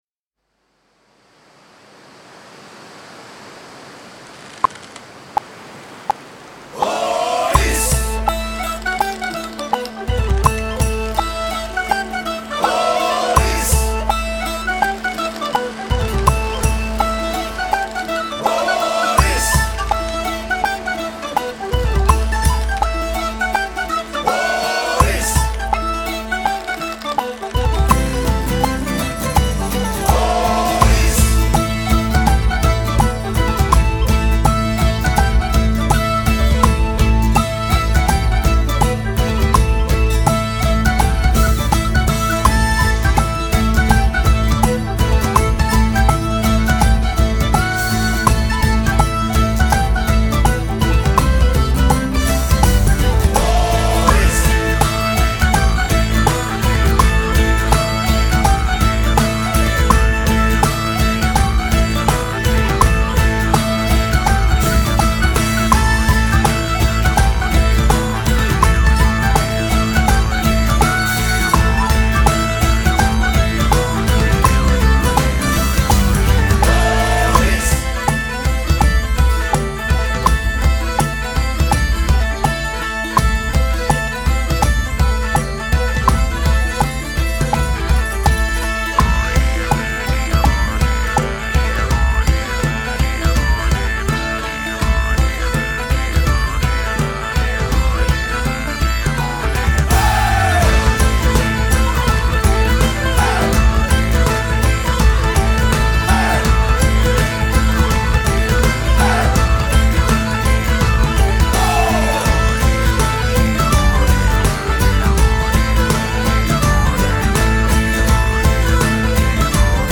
musique avec pulsation – partition visuelle